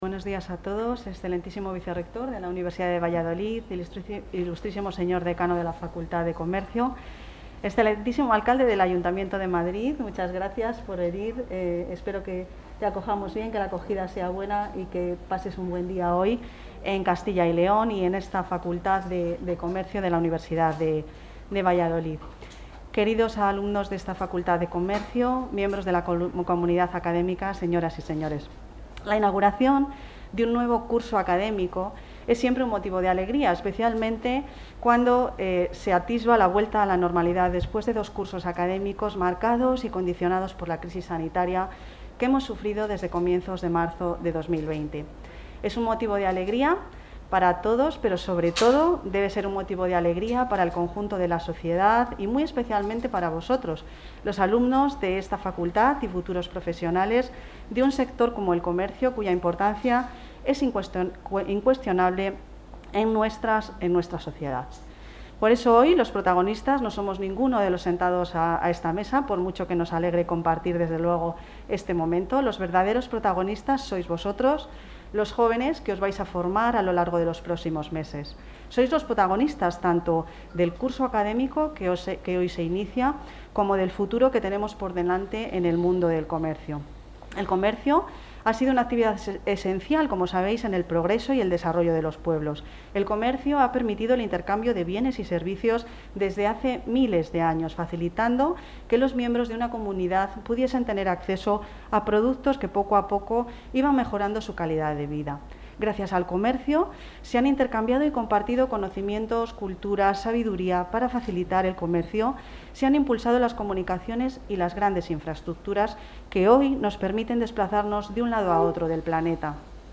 Material audiovisual de la apertura del curso de la Facultad de Comercio
Intervención de la consejera de Empleo e Industria.